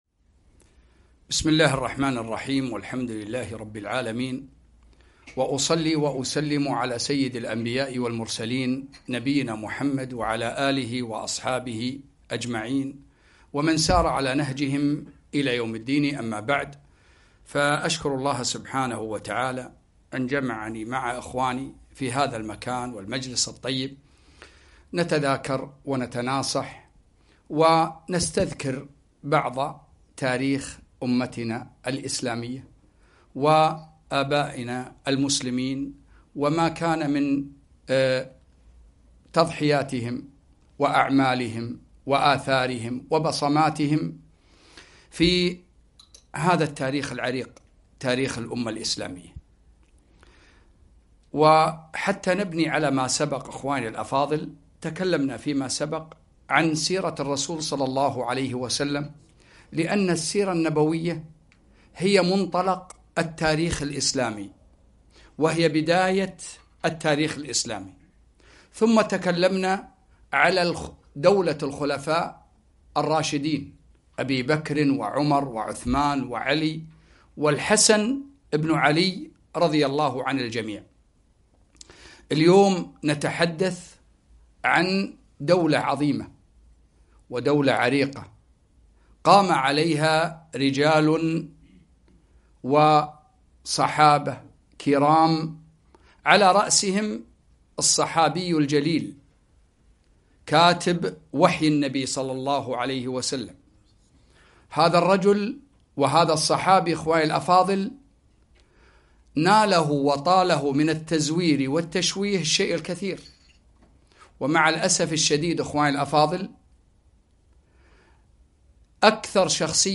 3- اختصار تاريخ المسلمين - الدولة الأموية في دمشق البداية والنهاية في محاضرة واحدة